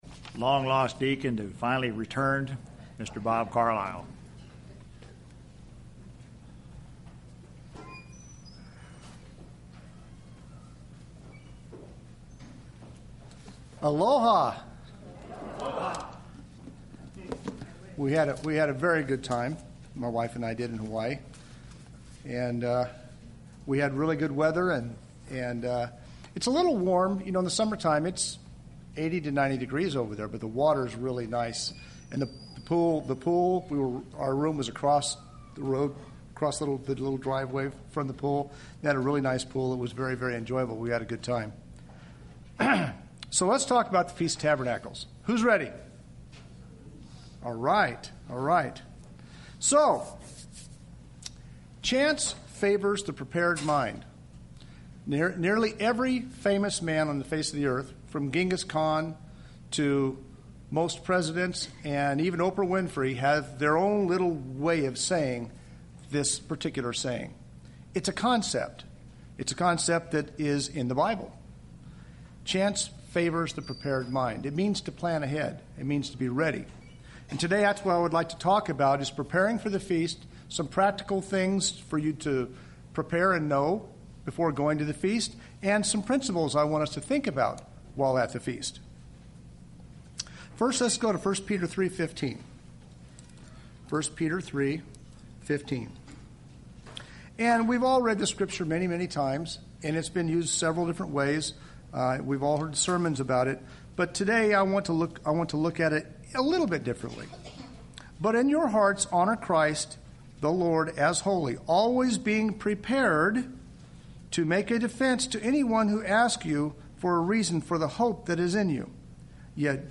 Given in Redlands, CA
Be a good example of one in the feast environment Jet lag and travel tips UCG Sermon Studying the bible?